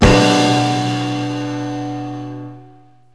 lrggong.wav